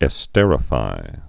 (ĕ-stĕrə-fī)